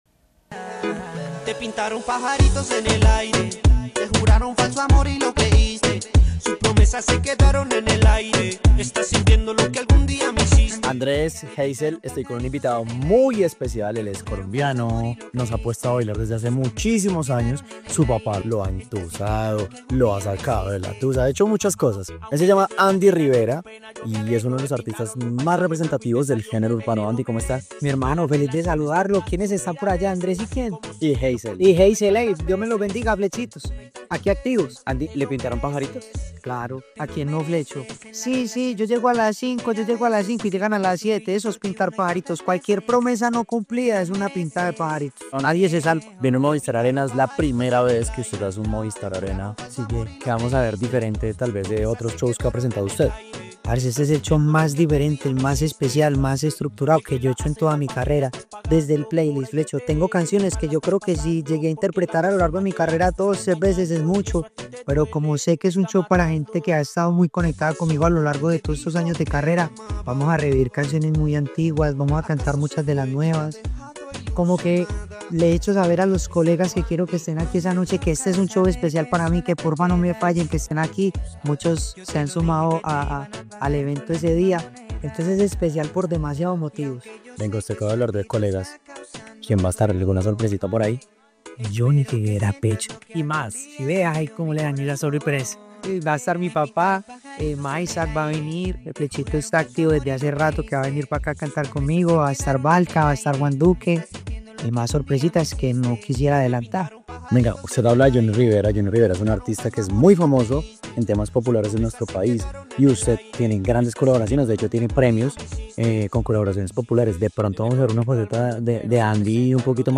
En entrevista con A Vivir que son Dos Días, el artista aseguró que este será un concierto muy especial en su carrera, “Este es el show más diferente, más especial y más estructurado que he hecho. Desde el playlist hemos trabajado para revivir canciones antiguas, cantar muchas de las nuevas e invitar a colegas a que me acompañen. Muchos ya se han sumado”.